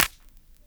THEY_clap_sizzle.wav